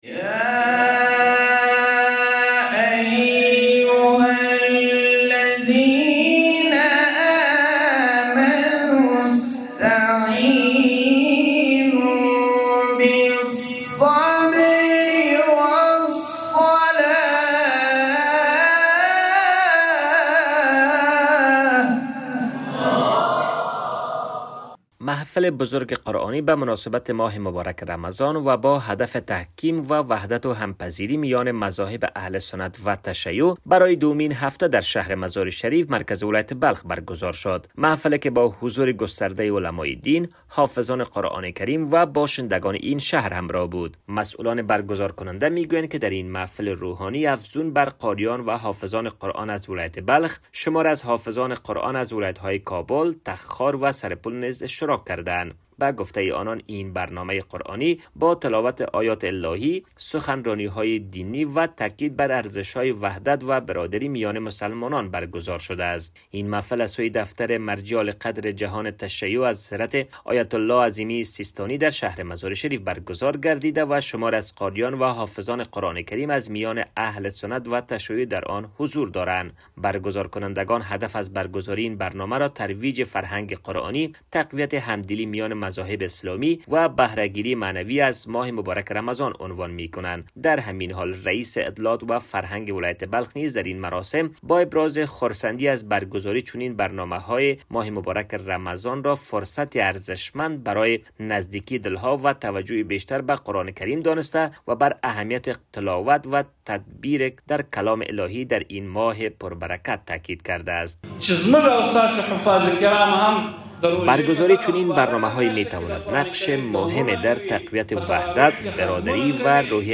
خبرنگار رادیو دری